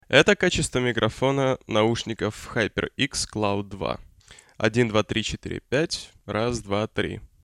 3.3 Kingston HyperX Cloud II тест микрофона (+сравнение)
Микрофон Kingston HyperX Cloud II после теста показался мне отличным по качеству. Речь считывает чисто.
Я считаю что Cloud Alpha и Cloud II по качеству примерно на одном уровне.